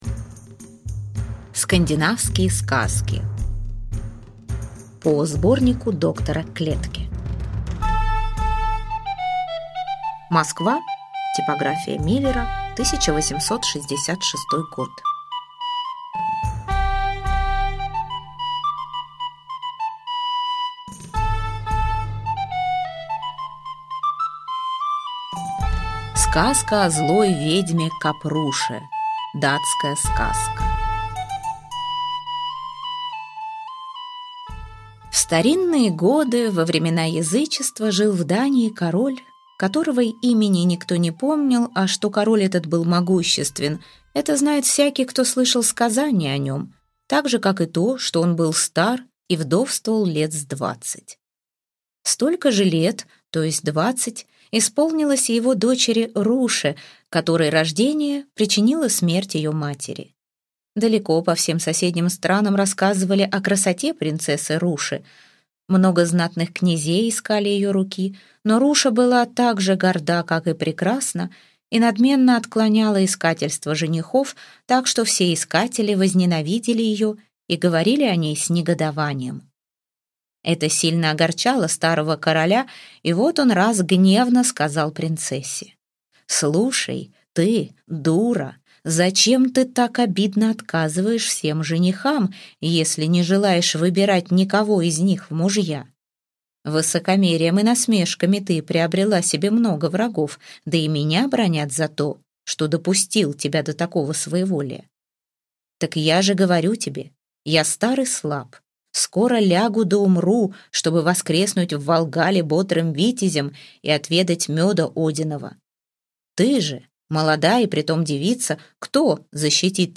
Аудиокнига Скандинавские сказки | Библиотека аудиокниг